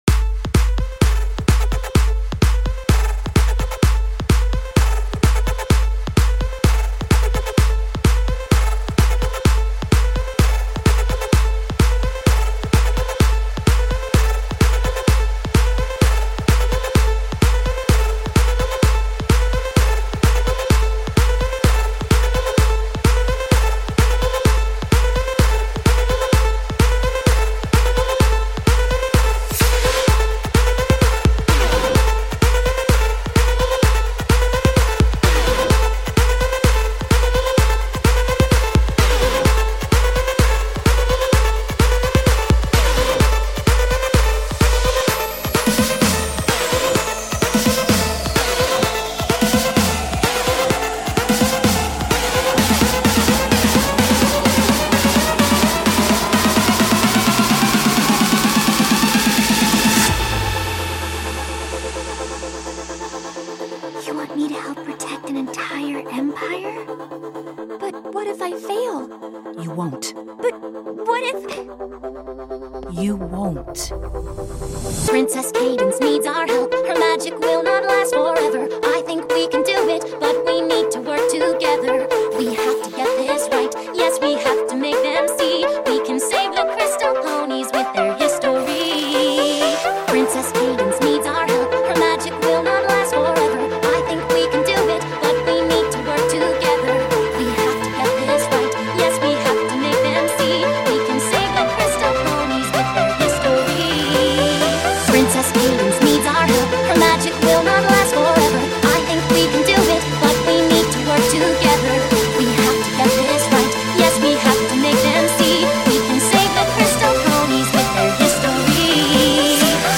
Here's my new mashup I just did.